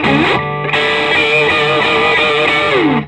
Index of /90_sSampleCDs/USB Soundscan vol.22 - Vintage Blues Guitar [AKAI] 1CD/Partition C/04-SOLO D125